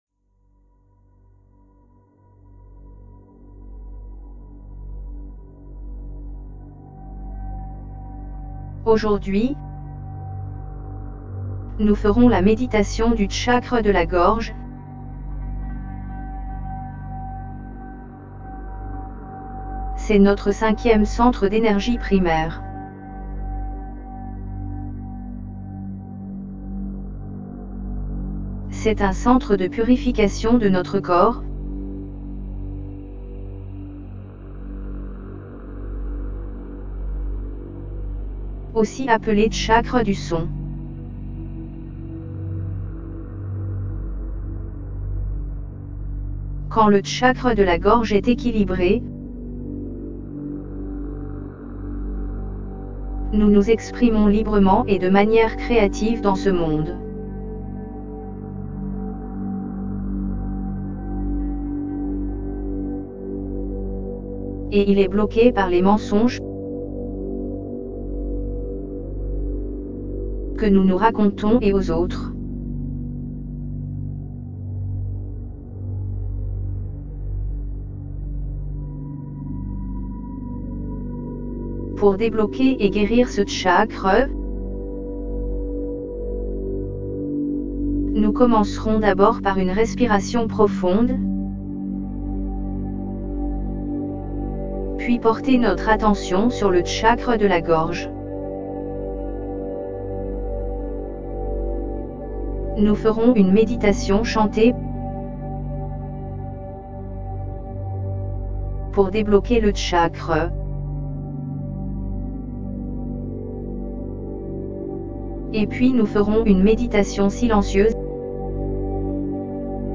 5ThroatChakraHealingGuidedMeditationFR.mp3